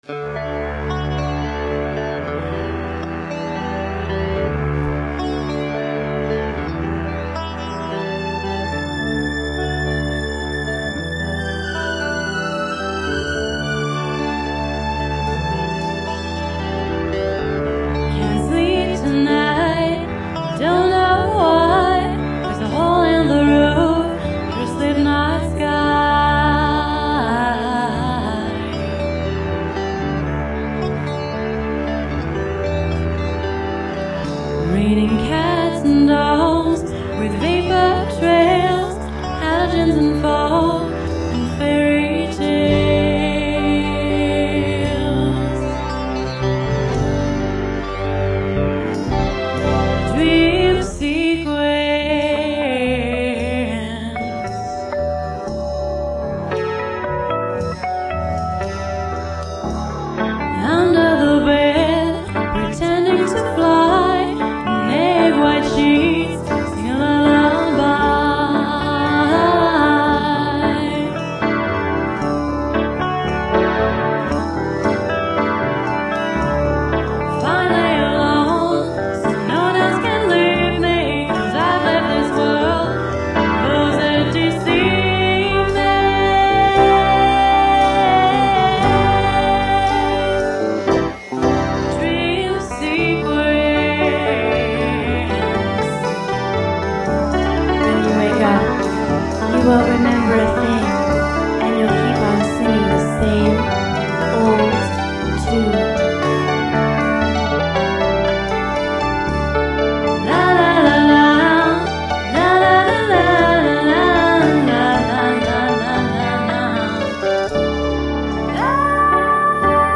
(original cast 4-track version)